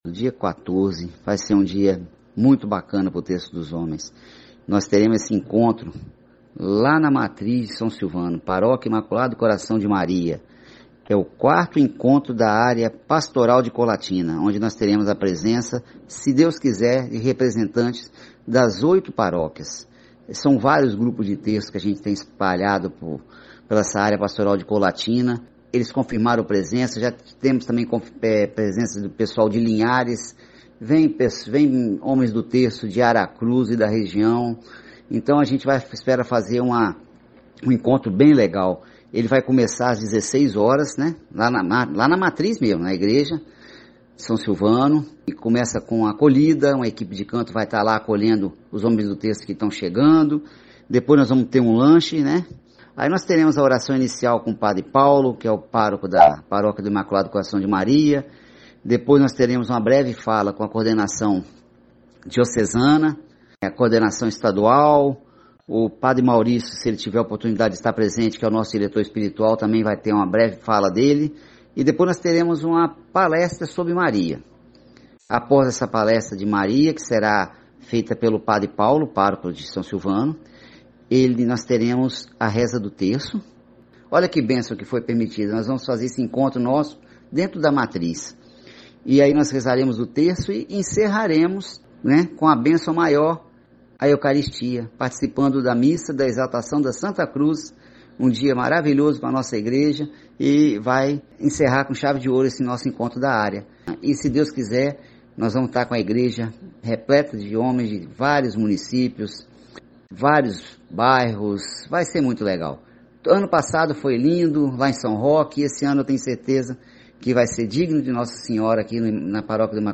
Pela primeira vez, adotamos o formato de entrevista com respostas em áudio (mp3).